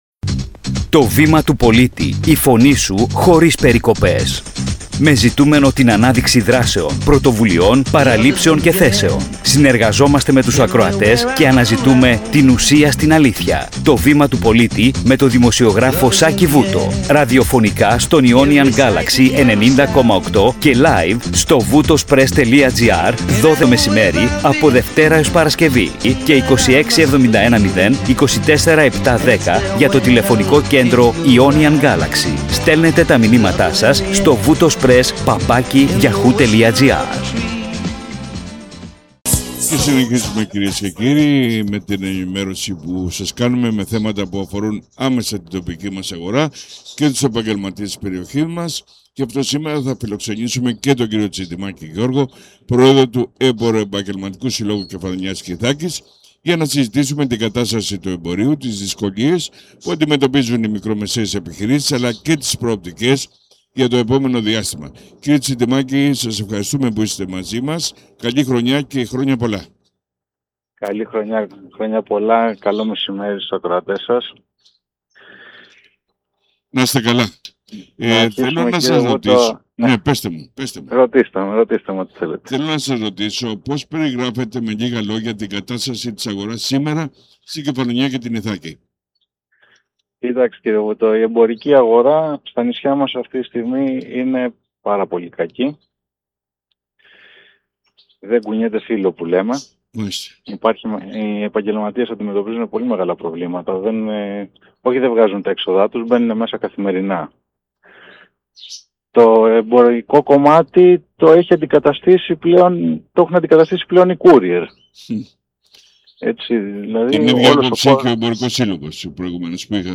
Περίληψη συνέντευξης